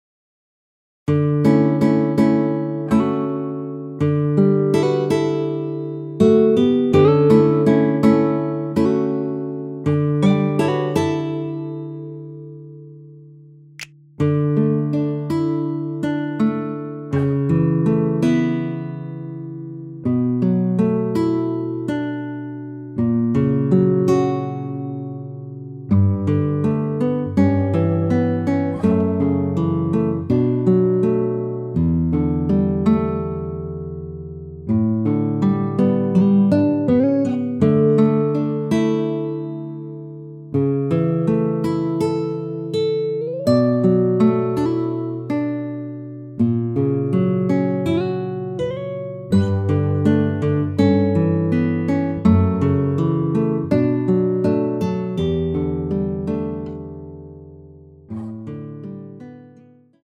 원키에서(-3)내린 MR입니다.
D
앞부분30초, 뒷부분30초씩 편집해서 올려 드리고 있습니다.